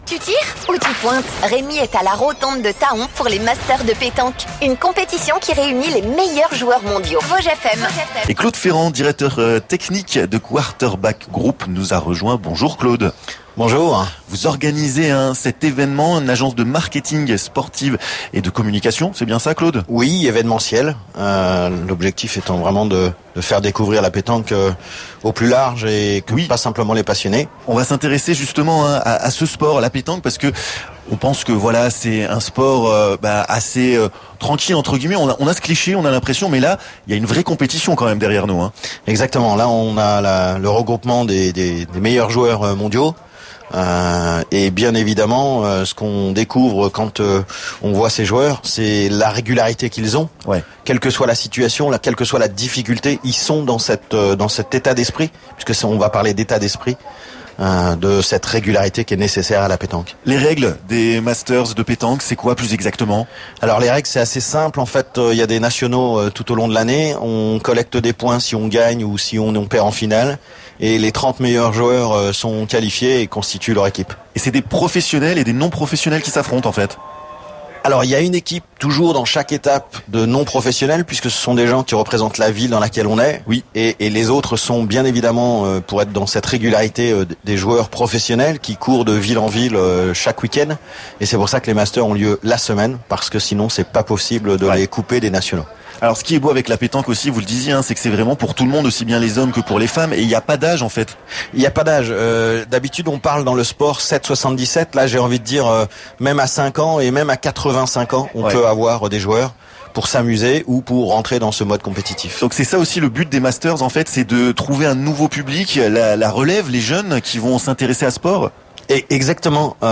Les "Vosges et vous" en direct des Masters de pétanque de Thaon, c'était ce jeudi 13 juillet! De nombreux invités sont venus à notre micro, dont le champion du monde Dylan Rocher!